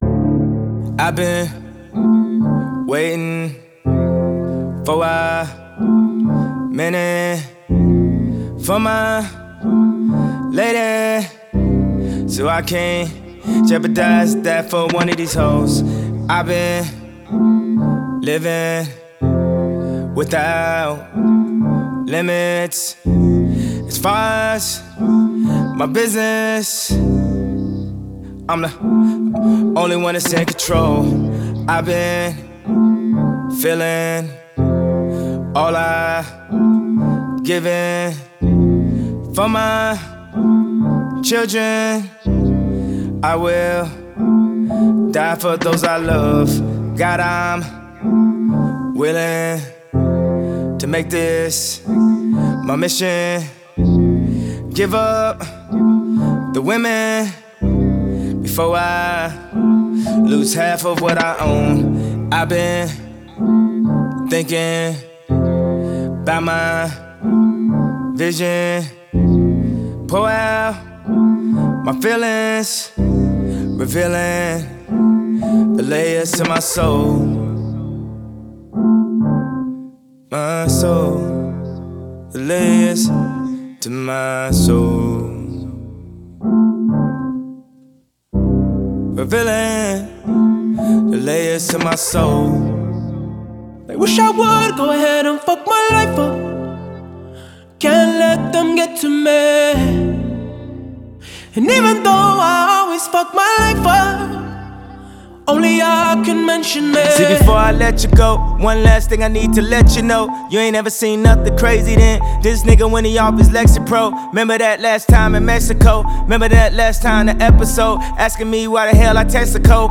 The mix of gospel, soul, and hip-hop